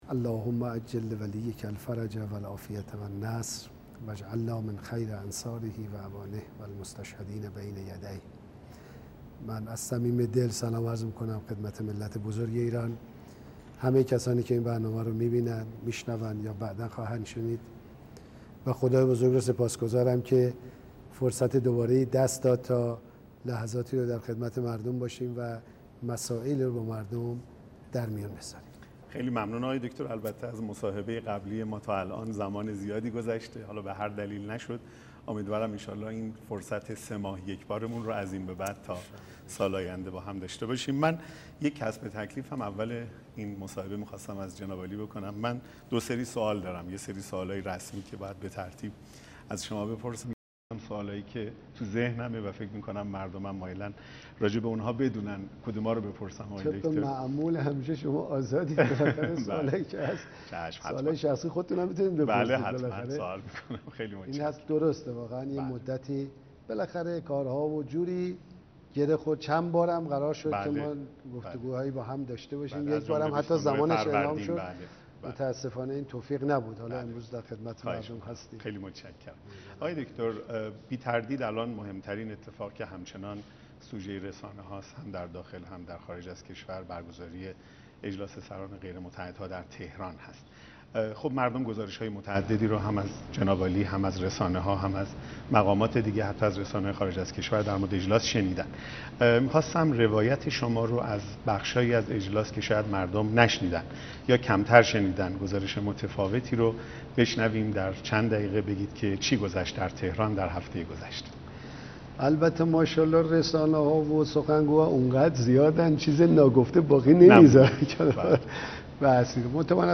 محمود احمدی نژاد رئیس جمهور ایران سه شنبه شب در گفتگوی زنده در شبکه یک سیما شرکت کرد و به سؤالات پاسخ داد.